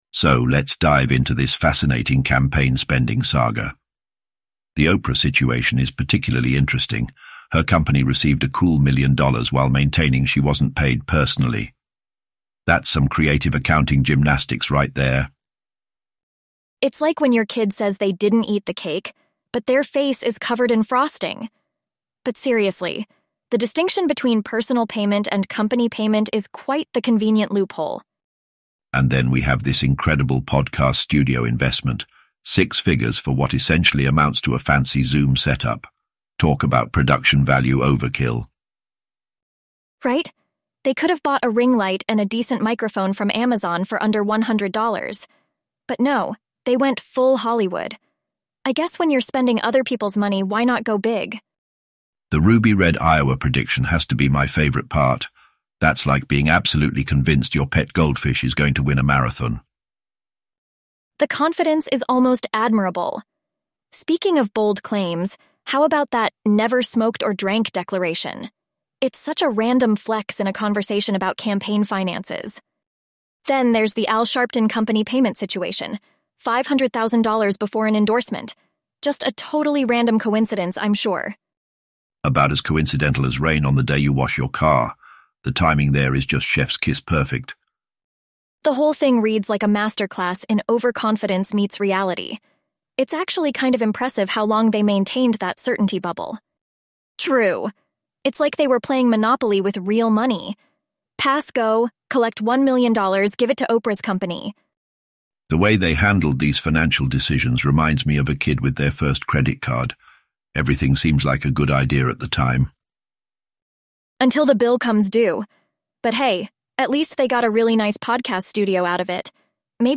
AI podcast summary from a youtube video using Anthropic or XAI and Elevenlabs voices